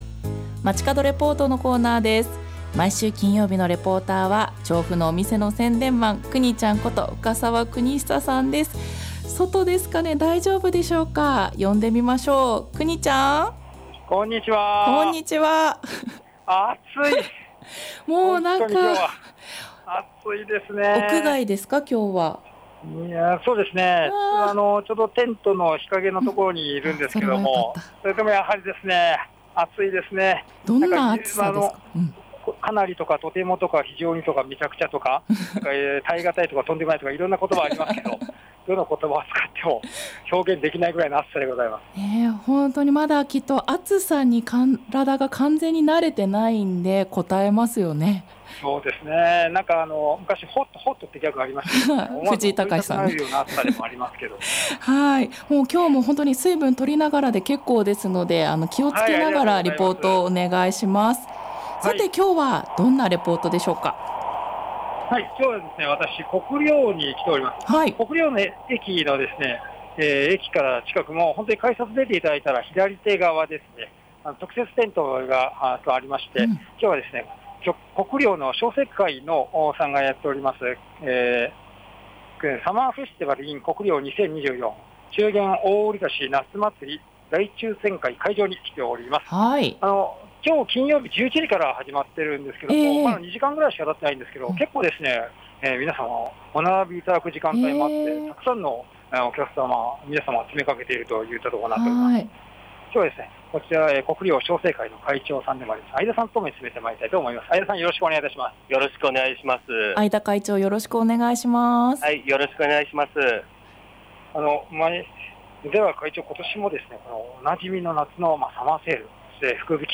国領商盛会サマーフェスティバルin国領2024 中元大売り出し夏まつり大抽選会会場にお邪魔しました。
もちろんレポート中でも鐘が鳴り響いていました。